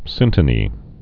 (sĭntə-nē)